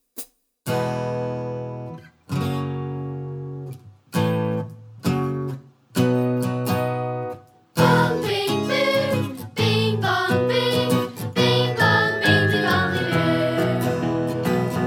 Extended With Kids, No Teacher Comedy/Novelty 1:27 Buy £1.50